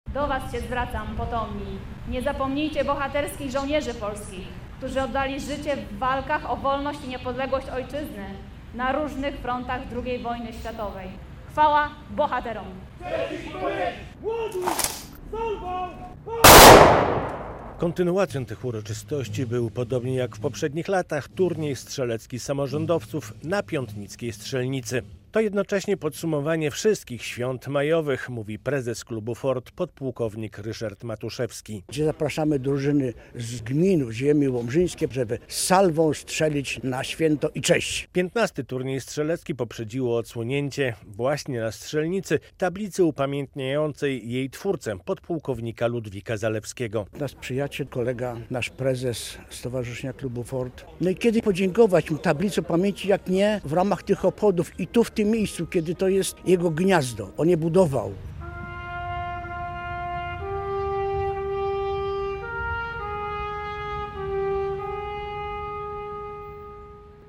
Oficjalne uroczystości z okazji 80. rocznicy zakończenia II wojny światowej na ziemi łomżyńskiej zorganizowano w Piątnicy, gdzie ponad 60 lat temu ustawiono pomnik - głaz upamiętniający żołnierzy - obrońców z 1939 r. Był apel pamięci, salwa honorowa w wykonaniu kompanii honorowej 18. Łomżyńskiego Pułku Logistycznego przemówienia i składanie kwiatów, a potem turniej strzelecki, który od czwartku (8.05) już oficjalnie nosi imię ppłk Ludwika Zalewskiego.